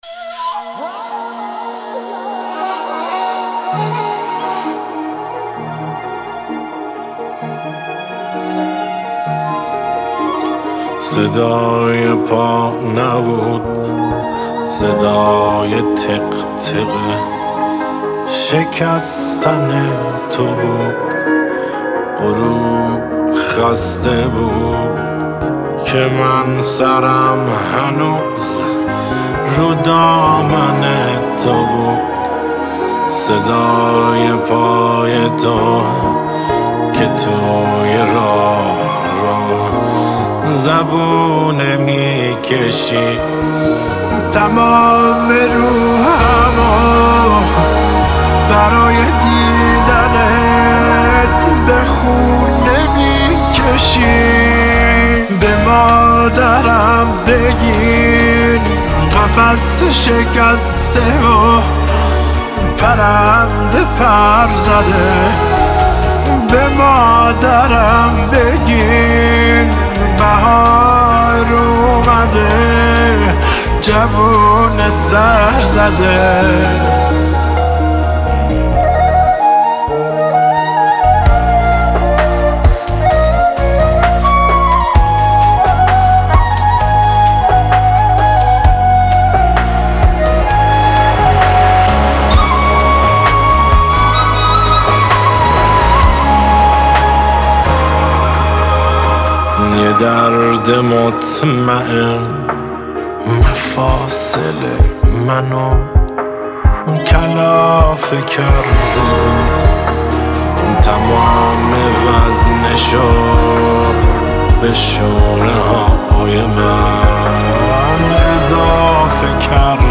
آهنگ فوق العاده احساسی و دلنشین
با صدای گرم هنرمند محبوب